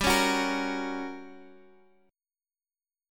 GmM7b5 chord